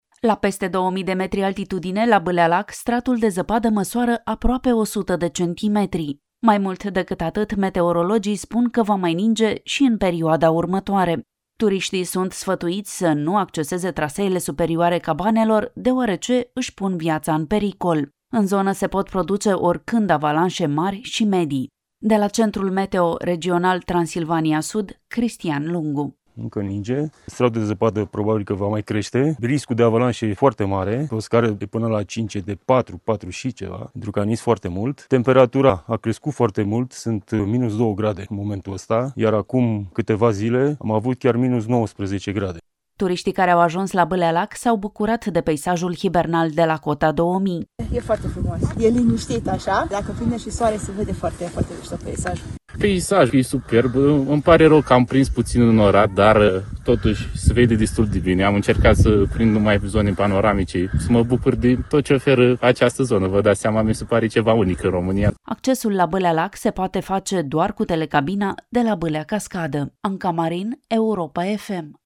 „E foarte frumos, e liniștit. Dacă prindem și soare se vede foarte mișto peisajul”, a spus o turistă.
„Peisajul e superb! Îmi pare rău că am prins puțin înnorat, dar se vede destul de bine. Am încercat să prind numai zone panoramice, să mă bucur de tot ce oferă această zonă. Mi se pare ceva unic în România”, a declarat un turist.